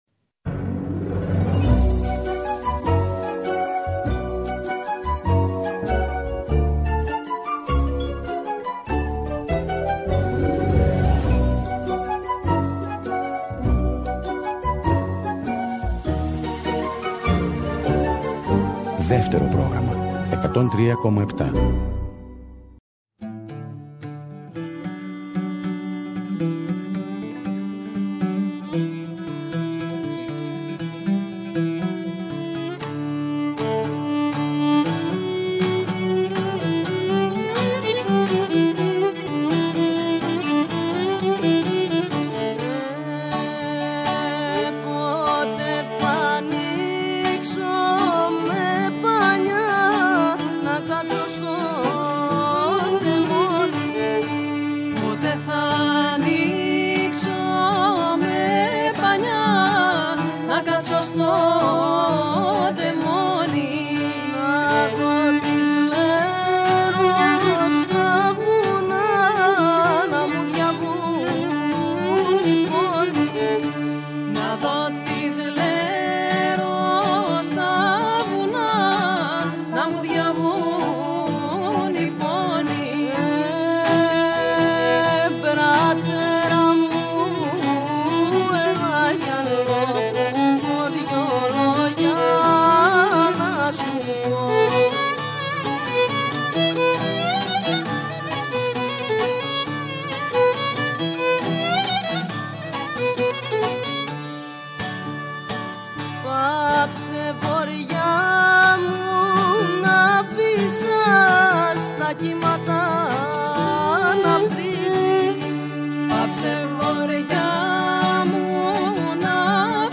Τρία ταλαντούχα κορίτσια, που λατρεύουν το μουσικό…
Οι « Κουμπάρες » λοιπόν, θα μας ταξιδέψουν με παραδοσιακά τραγούδια από διάφορες περιοχές της Ελλάδας, προσεγγίζοντάς τα με το απαράμιλλο αθηναϊκό τους ταμπεραμέντο!
λαούτο
ούτι
βιολί
ηχογραφήθηκαν ζωντανά στο στούντιο Ε της Ελληνικής Ραδιοφωνίας